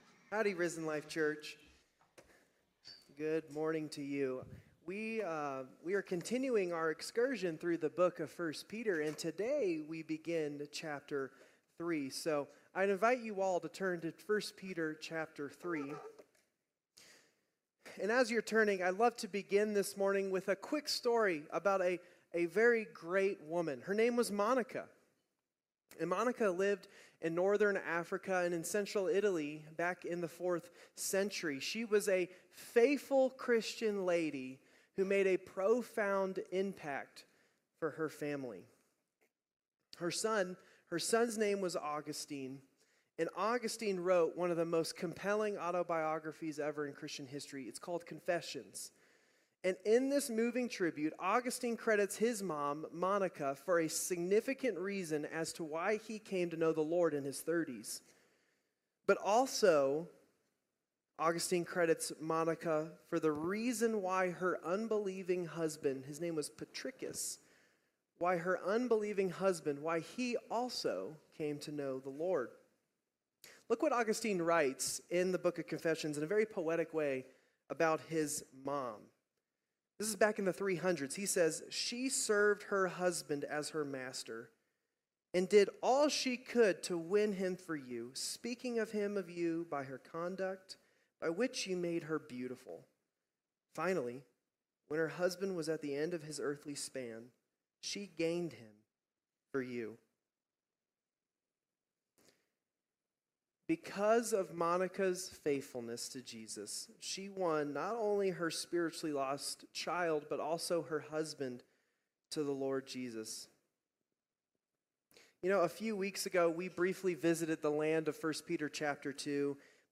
All Sermons - Risen Life Church